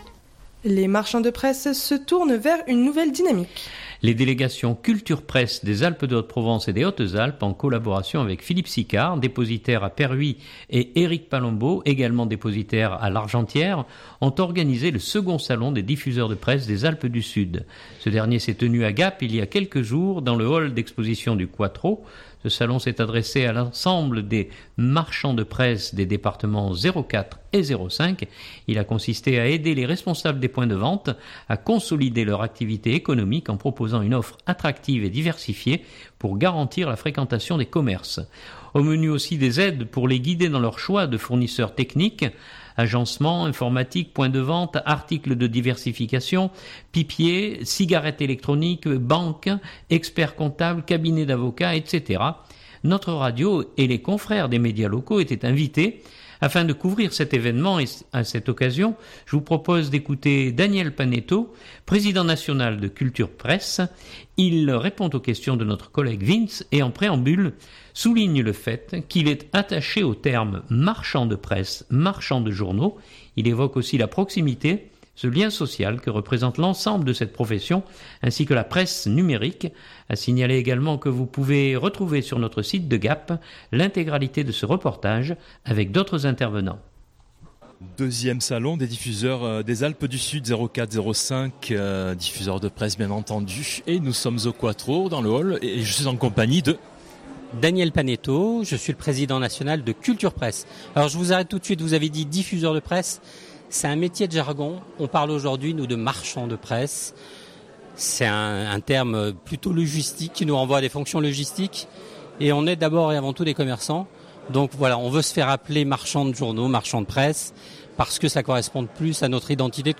Ce dernier s’est tenu à Gap, il y a quelques jours, dans le Hall d’exposition du Quattro. Ce salon s’est adressé à l’ensemble des marchands de presse des départements 04 et 05, il a consisté à aider les responsables des points de vente à consolider leur activité économique en proposant une offre attractive et diversifiée pour garantir la fréquentation des commerces.